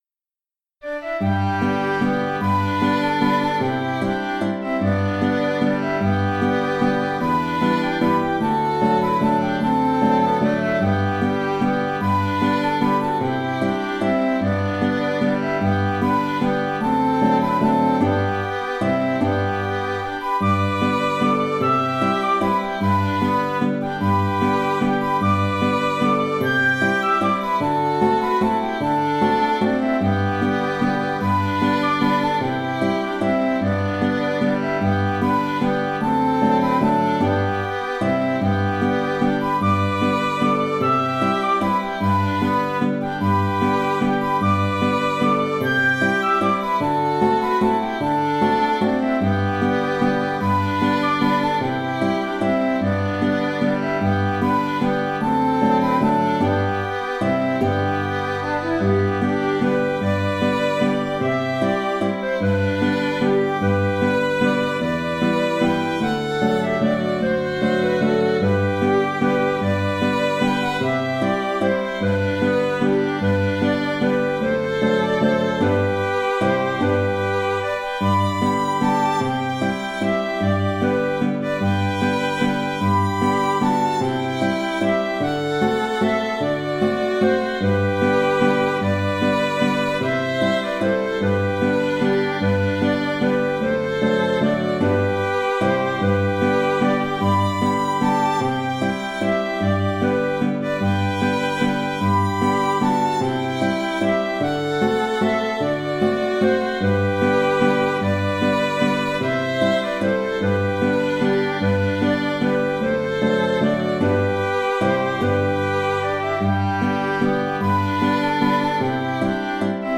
Moonlight variation (Valse écossaise) - Compositions
Elle est en fait le contrechant principal, qui a été porté à la 1ère ligne à l'octave supérieure, auquel j'ai rajouté un contrechant secondaire.
Le fichier mp3 comprend le thème, la variation, et le retour au thème.